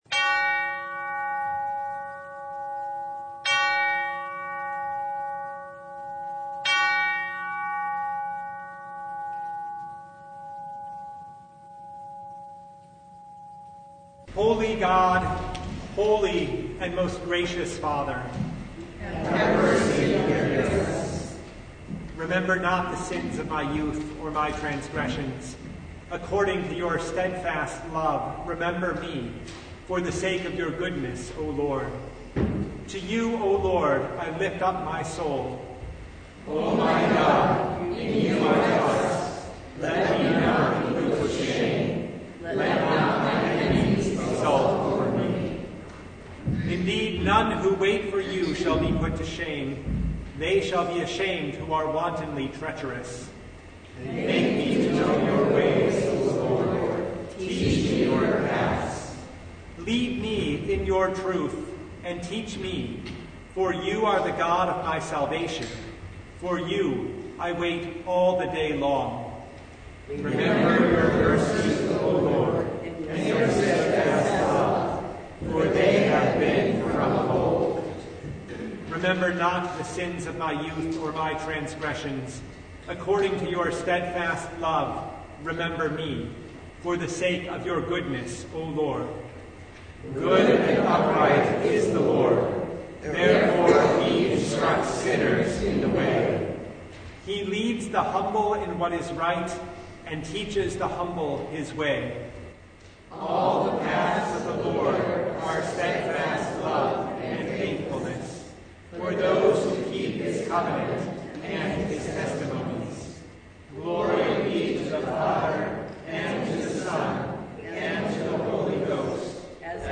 Service Type: Lent Midweek Noon
Full Service